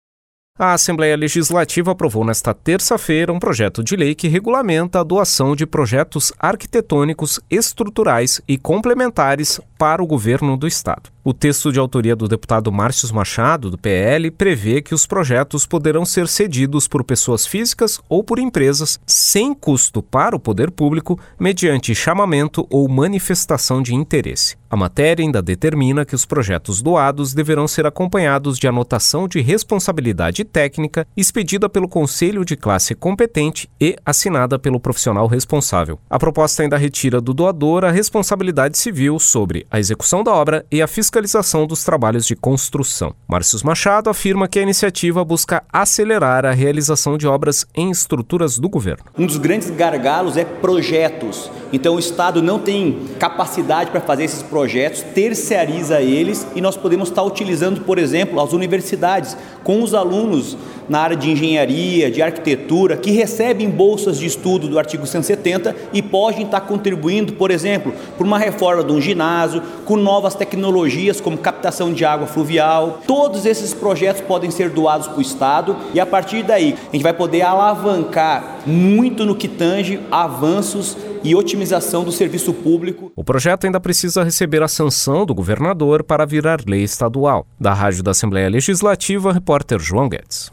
Entrevista com:
- deputado Marcius Machado (PL).